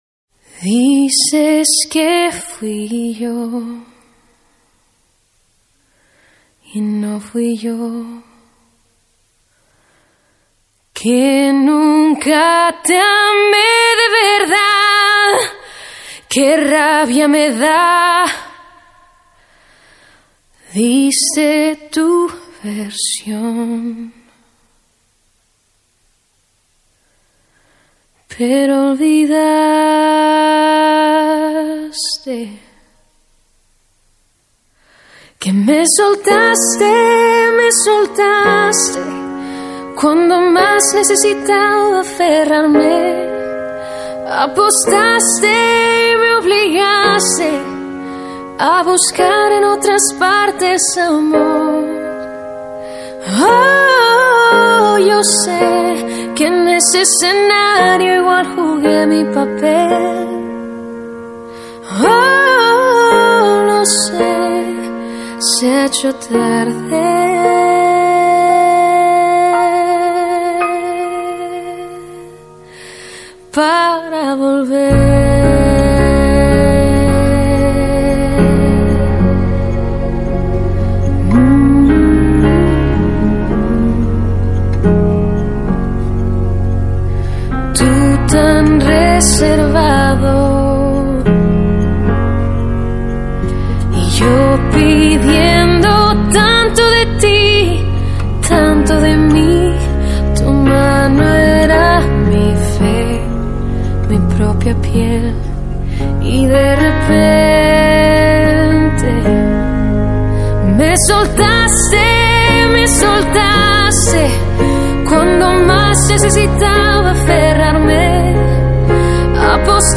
Carpeta: Lentos en español mp3